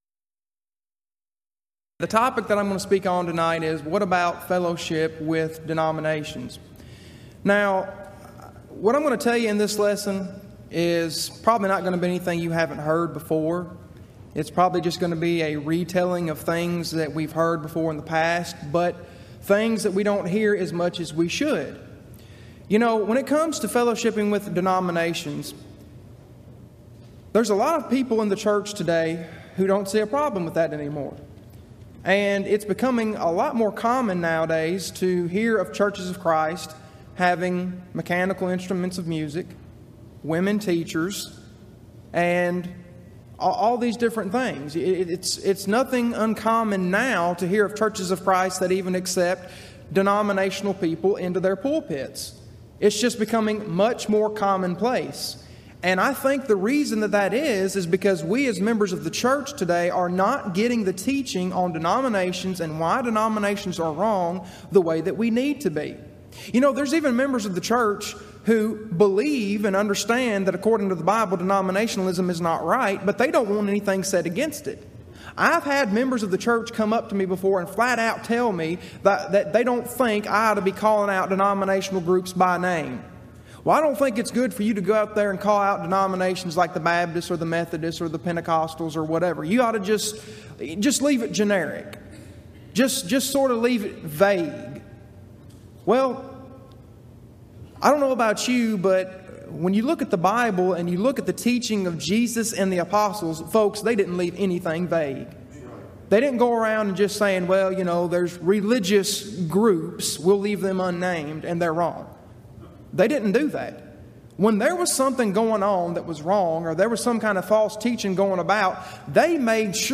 Event: 24th Annual Gulf Coast Lectures